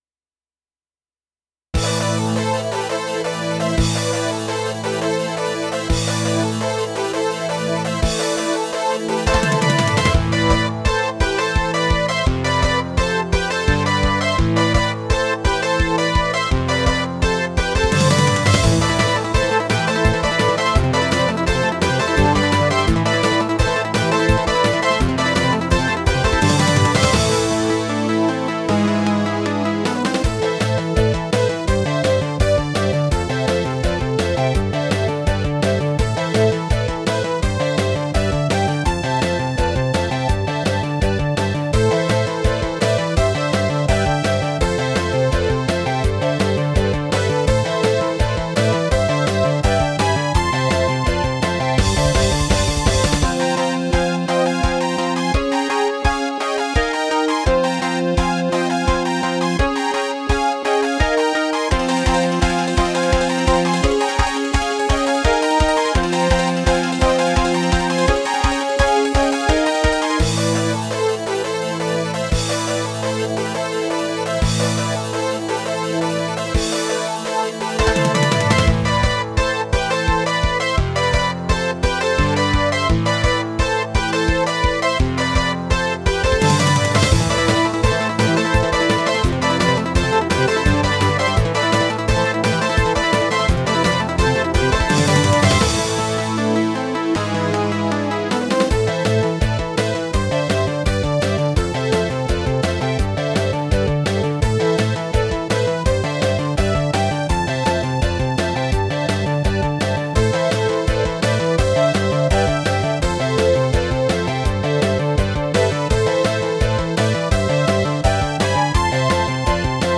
由Roland Sound Canvas 88Pro实机录制；MP3采样率44.1KHz，码率192Kbps。